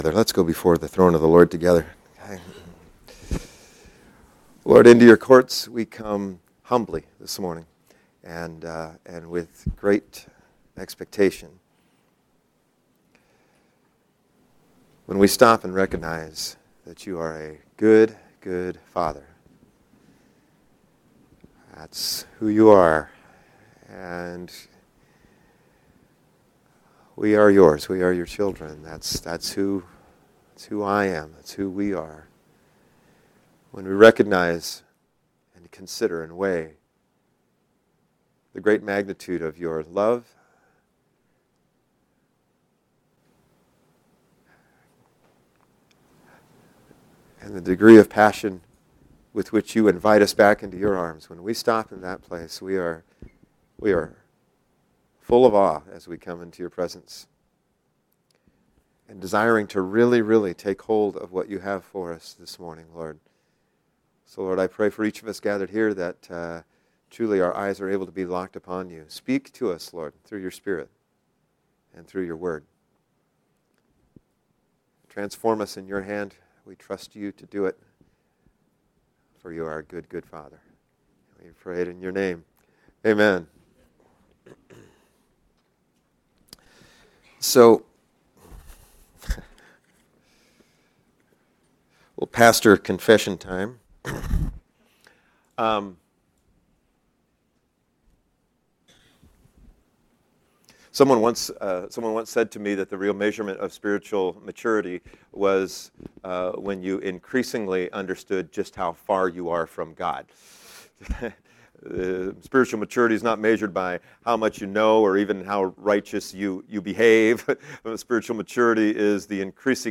Sermons | Lighthouse Covenant Church